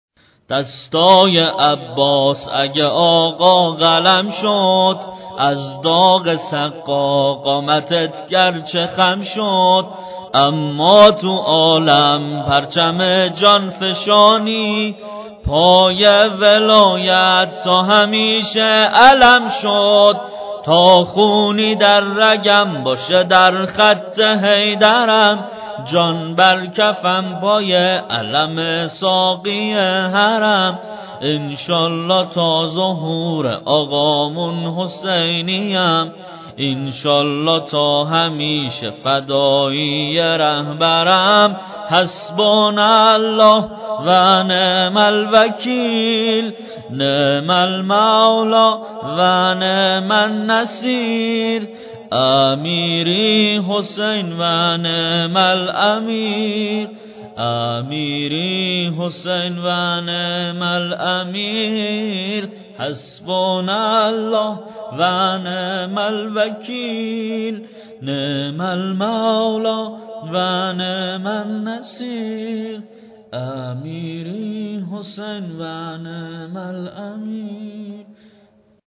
واحد (حماسی)